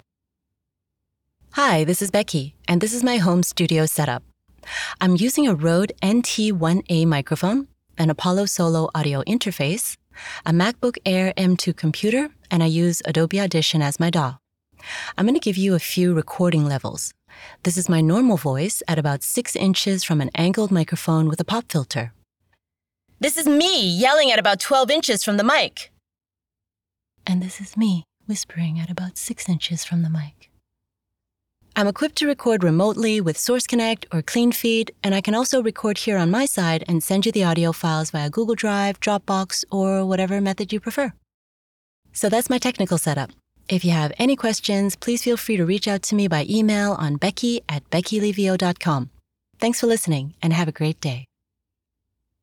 Home Studio Setup